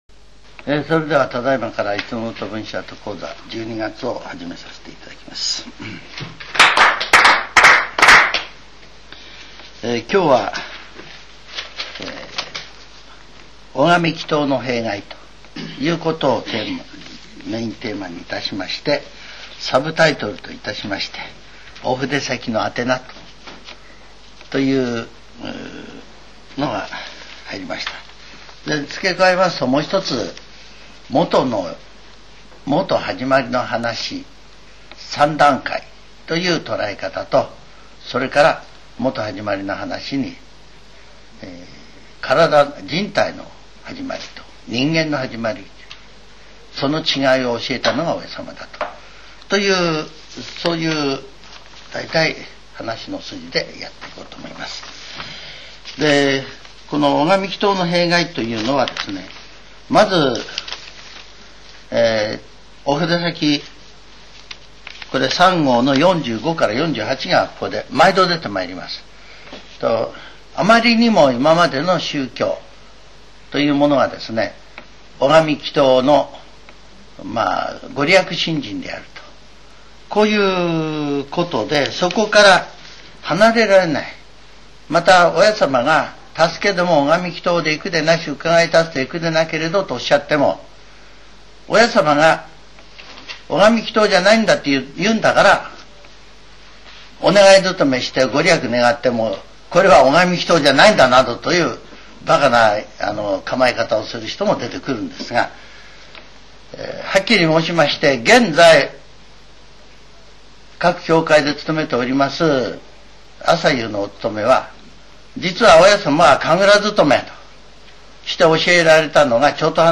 全70曲中39曲目 ジャンル: Speech